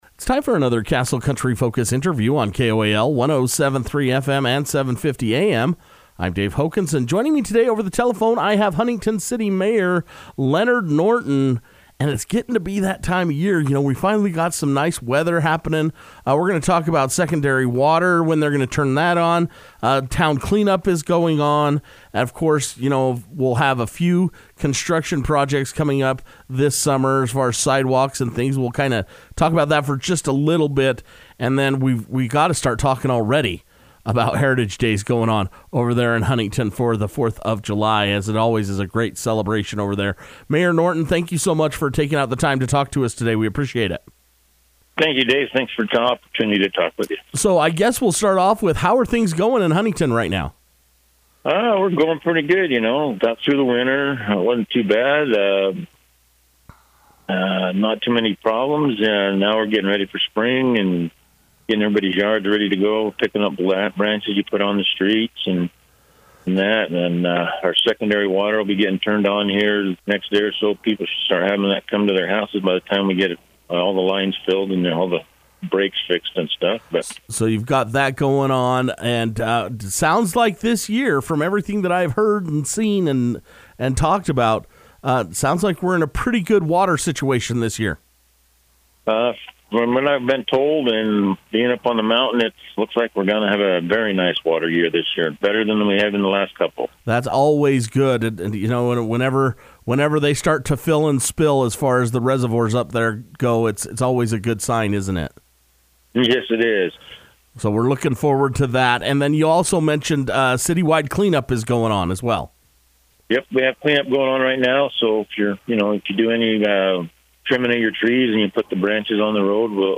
It’s time for another Huntington City update with Mayor Leonard Norton who took time to speak over the telephone with Castle Country Radio to discuss secondary water, city wide clean-up, road/sidewalk projects and Heritage Days